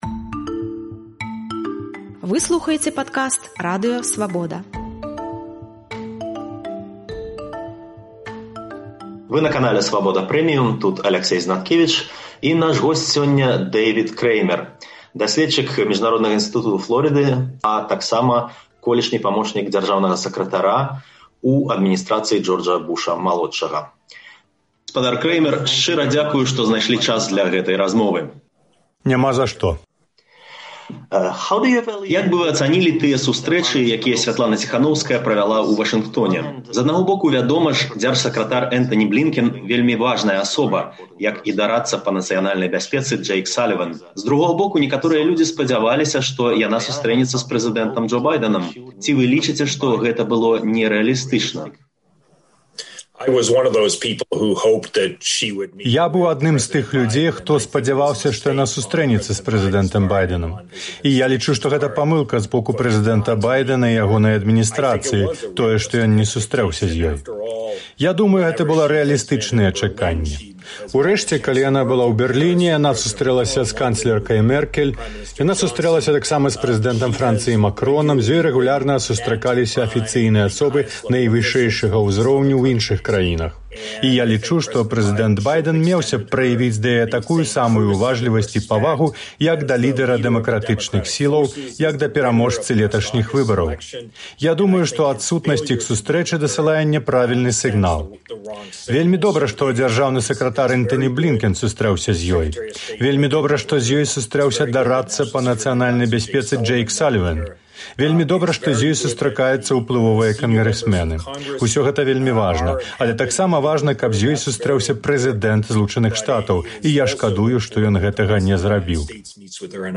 Дэйвід Крэймэр, дасьледчык Міжнароднага ўнівэрсытэту Флорыды і былы памочнік дзяржсакратара ЗША ў адміністрацыі Джорджа Буша-малодшага, адказаў на пытаньні Радыё Свабода. Ён расказаў пра чатыры мэты, якія ЗША варта паставіць у адносінах да Беларусі, і пра тыя крокі, якія ён раіць зрабіць адміністрацыі Джо Байдэна.